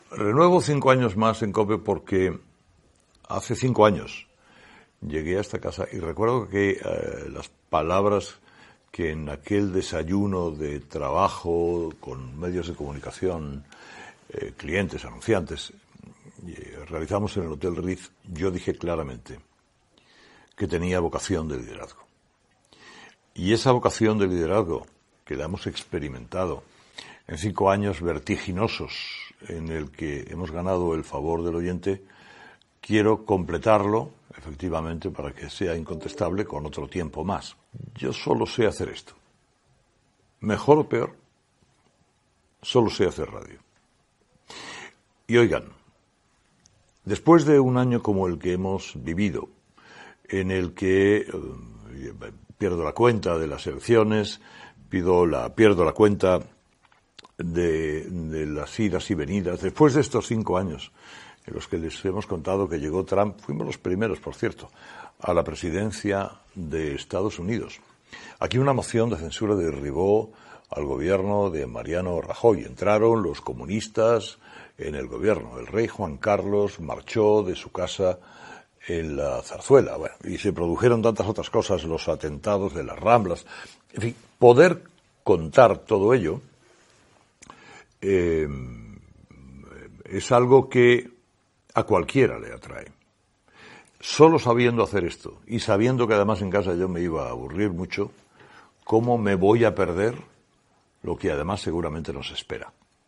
Carlos Herrera anuncia que ha renovat el seu contracte amb l'emissora per cinc anys
Info-entreteniment